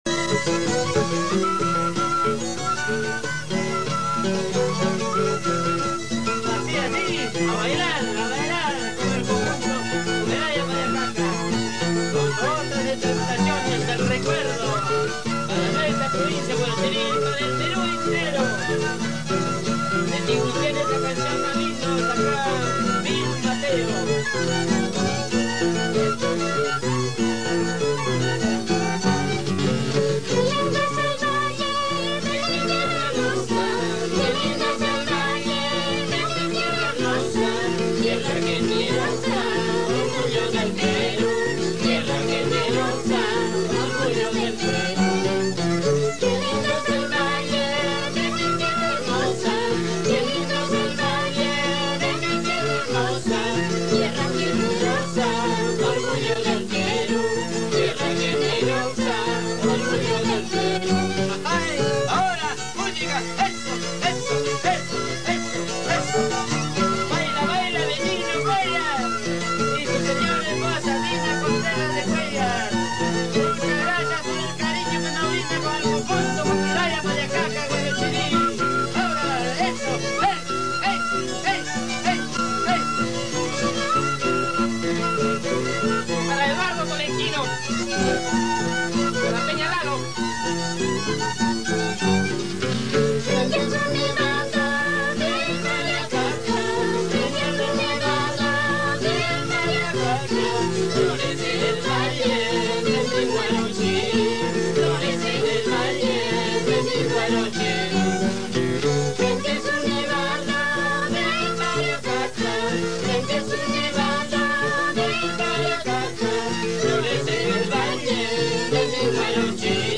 HUAYNOS